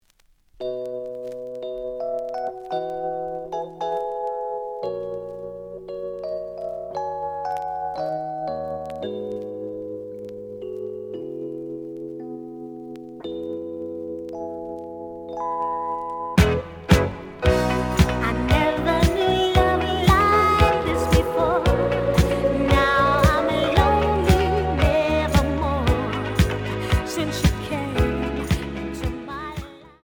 The audio sample is recorded from the actual item.
●Genre: Disco
Slight noise on beginning of B side, but almost good.)